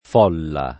f0lla o f1lla] s. f. — concordi nella pn. con -o- aperto la Tosc. fior., la Tosc. occid., Roma; in quella invece con -o- chiuso la Tosc. senese in senso largo e aretina; variam. discordi le altre regioni — folla ricavato da follare, verbo nelle sue forme rizotoniche ancóra legato, come il raro s. m. follo [f1llo], all’etimo lat. fullo -onis (con -u- breve) «lavandaio»; ma ormai, da tre secoli e più, passato dal primo sign. di «pressione, calca» a quello di «gran numero di persone», allentando quel legame e presentandosi, appunto dal primo ’700, con una pronunzia aperta che più o meno ha poi prevalso — connesse con folla anche le forme di affollare e sfollare accentate sul radicale